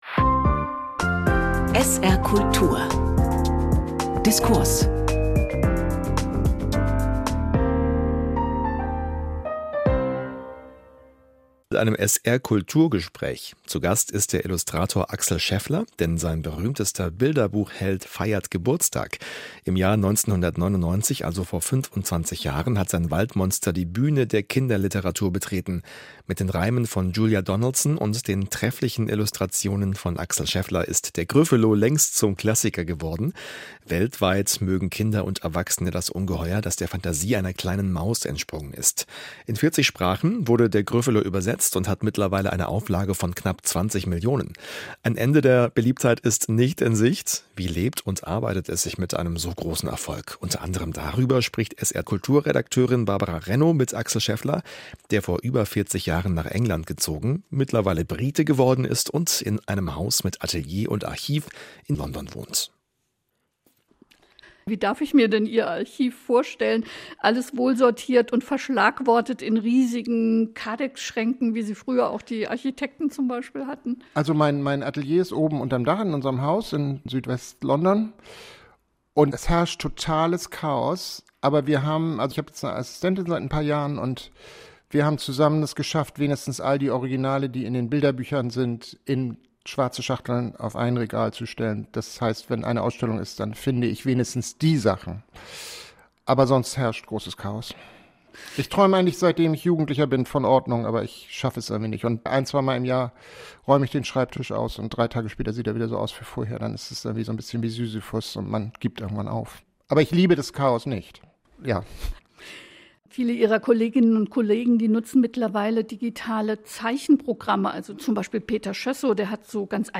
Mitschnitte von Diskussionsveranstaltungen zu aktuellen Themen aus Wissenschaft, Politik und Gesellschaft.